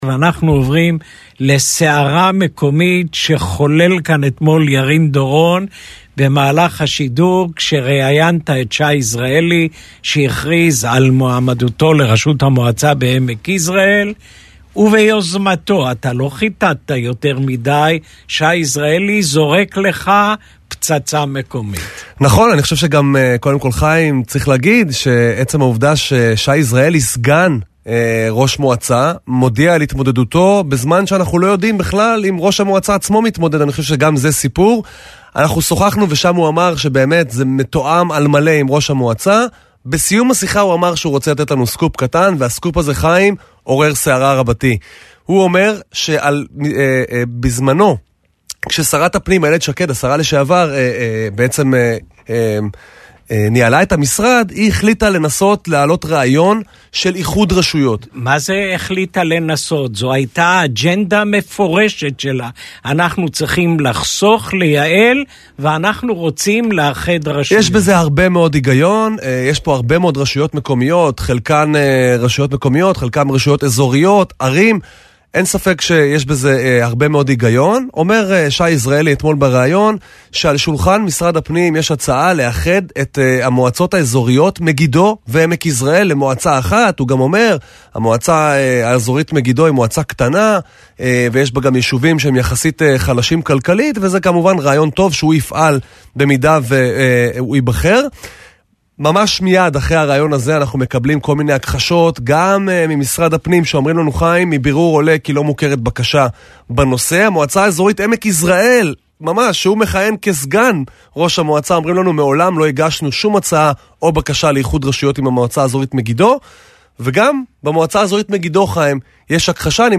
לראיון המלא: